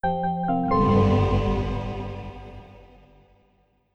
OS2 Warp 9.0 Startup.wav